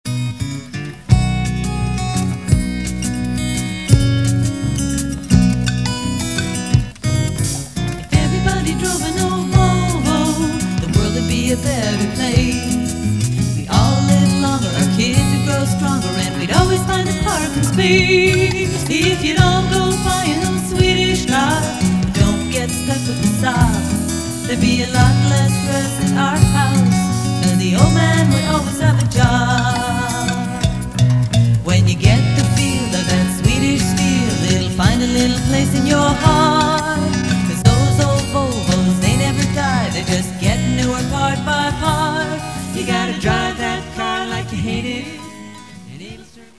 This "song of tribute" should load and play automatically (mp3 format).